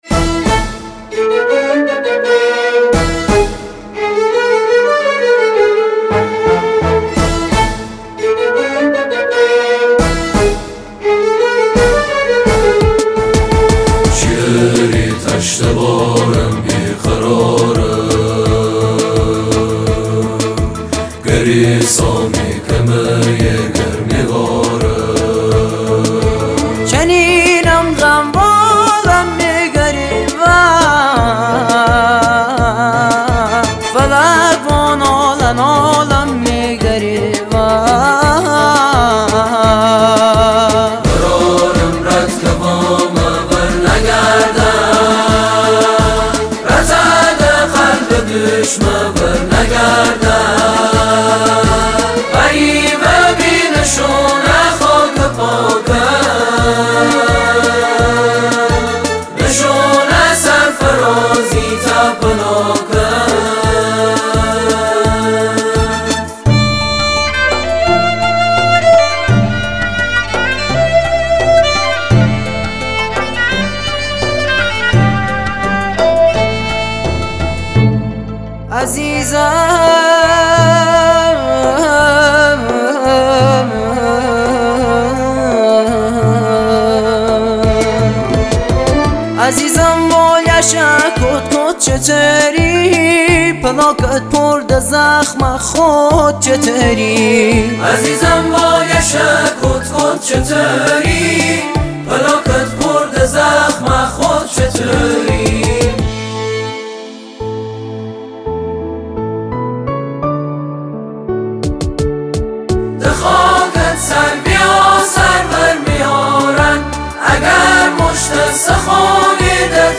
گروه سرود دانش آموزی
در ادامه این سرود زیبا را به گویش محلی «لری» می شنوید: